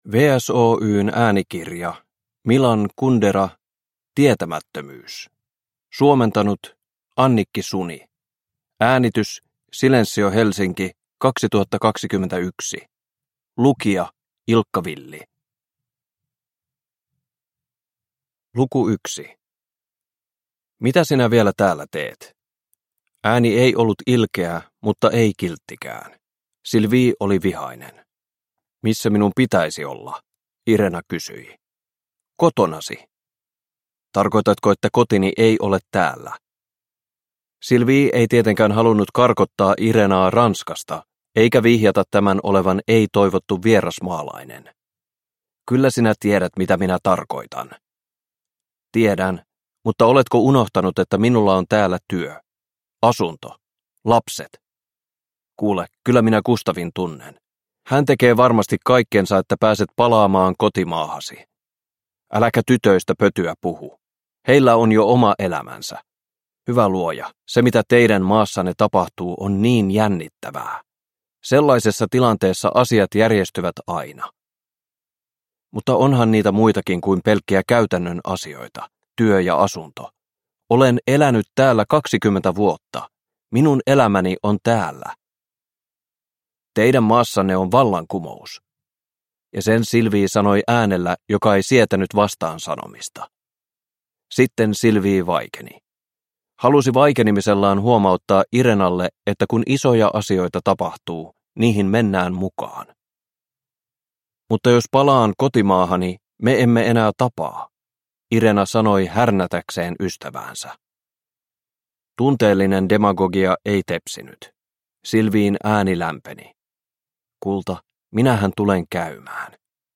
Tietämättömyys – Ljudbok – Laddas ner